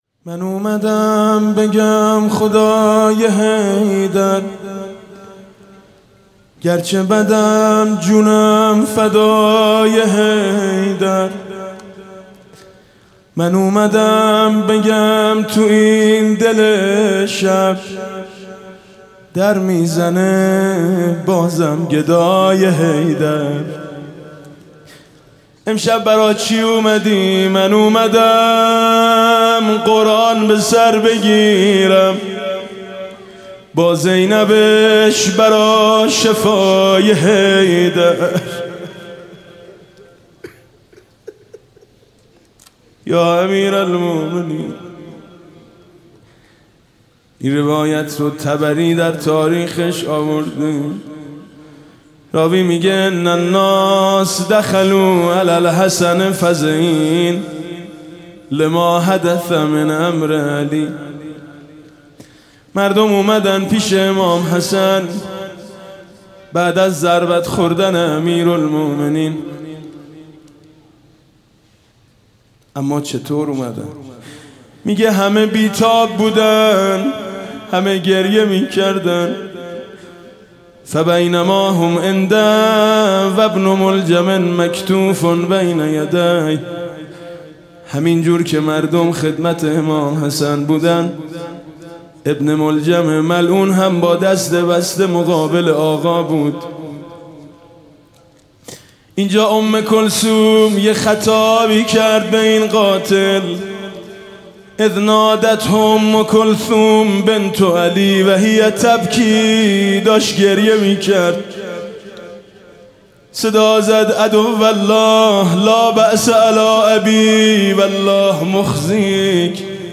شب بیست و یکم رمضان 96 - هیئت شهدای گمنام - مناجات - من اومدم بگم تو این دل شب، در می زنه بازم گدای حیدر